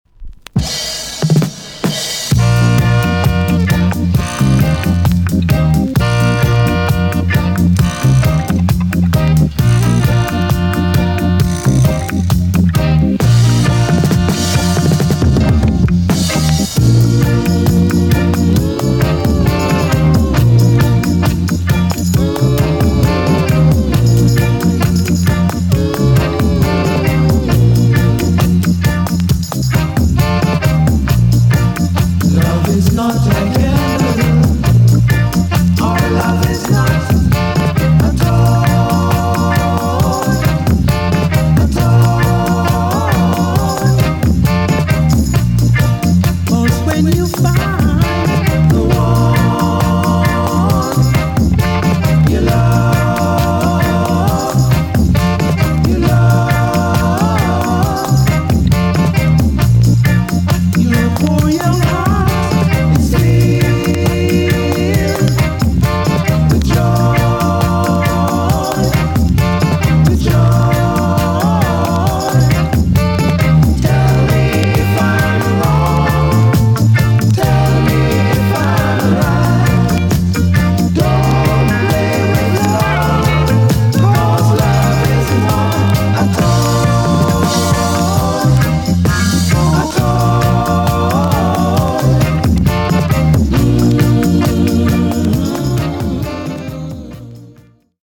EX- 音はキレイです。
UK , SOCA